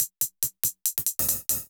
Index of /musicradar/ultimate-hihat-samples/140bpm
UHH_ElectroHatC_140-04.wav